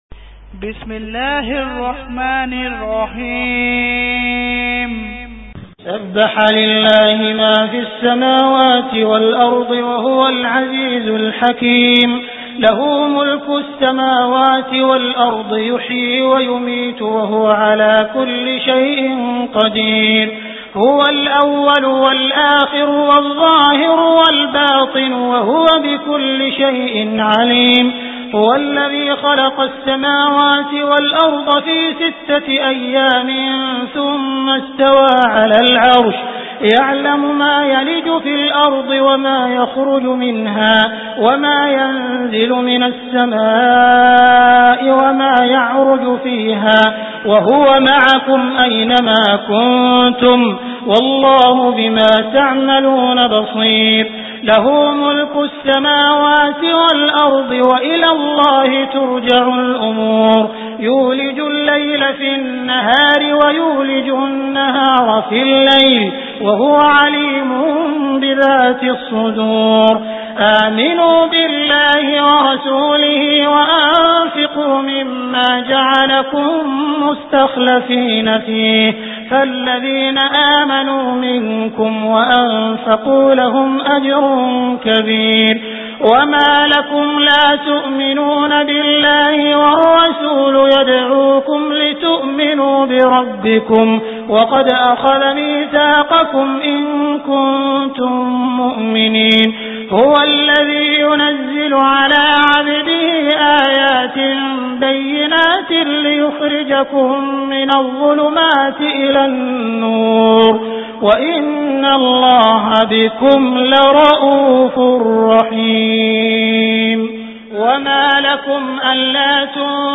Surah Al Hadid Beautiful Recitation MP3 Download By Abdul Rahman Al Sudais in best audio quality.